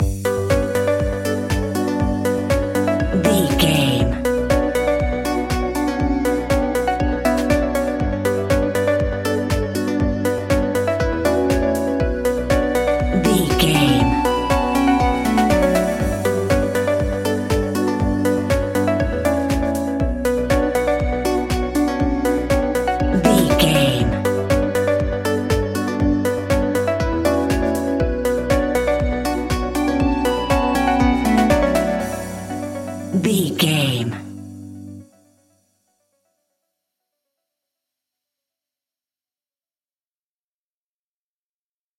Aeolian/Minor
Slow
groovy
peaceful
tranquil
meditative
smooth
drum machine
electric guitar
synthesiser
synth leads
synth bass